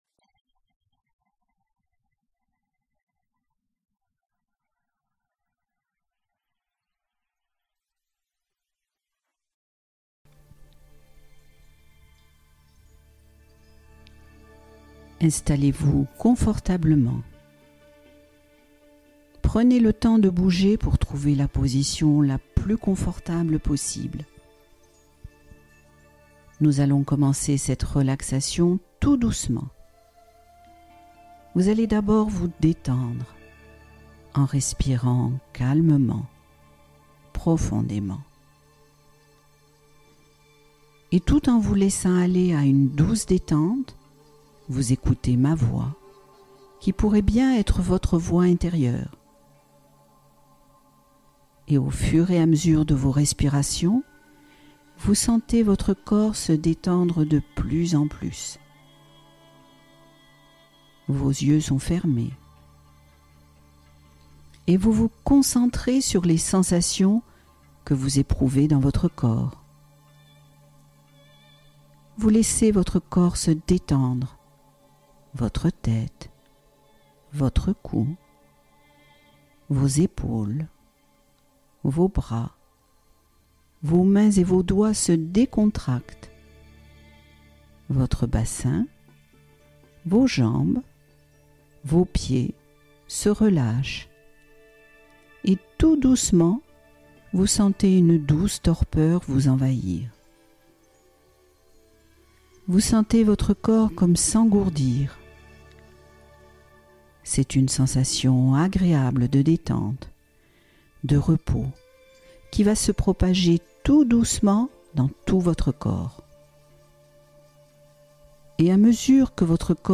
Repos réparateur profond — Relaxation guidée pour récupérer pleinement